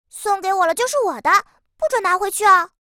贡献 ） 协议：Copyright，人物： 碧蓝航线:肇和语音 2022年5月27日